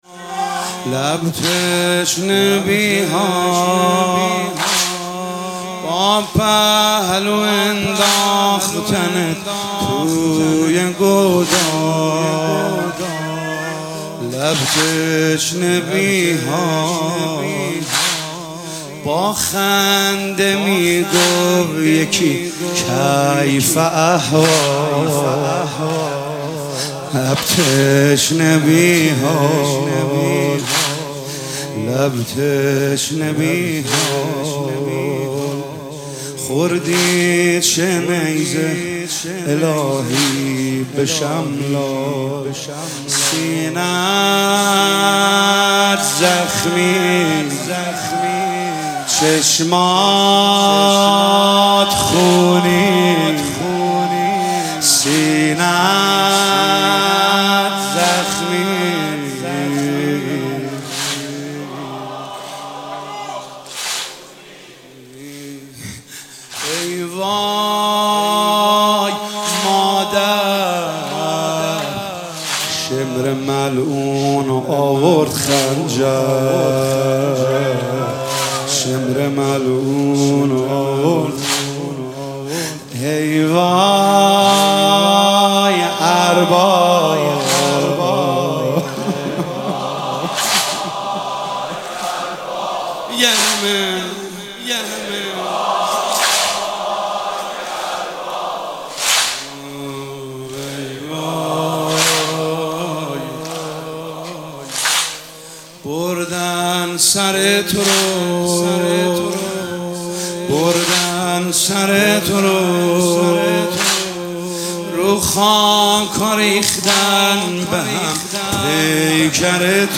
مداحی واحد شب دهم محرم 1445